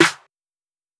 Southern Snare.wav